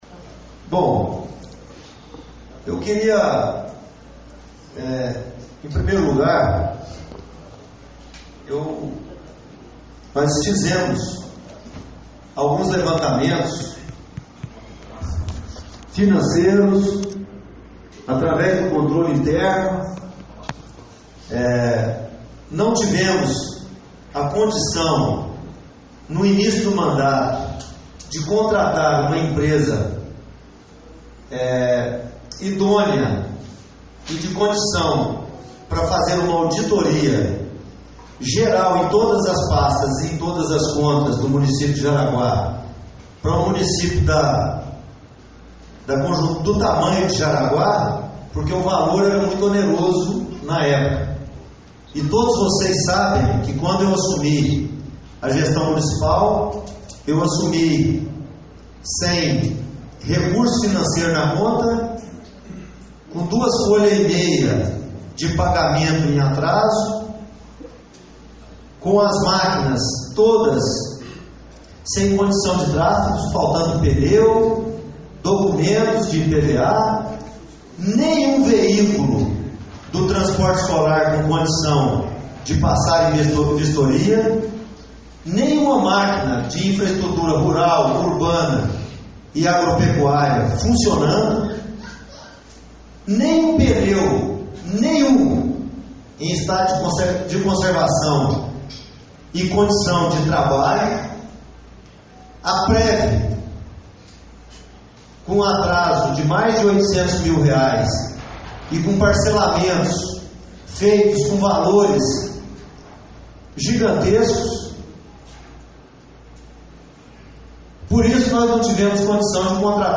Em entrevista coletiva à imprensa local nesta terça-feira, (9), o prefeito Zilomar Oliveira (PSDB) apresentou dados sobre gastos de recurso público na gestão do ex-prefeito Ival Danilo Avelar (PTB)
coletiva.mp3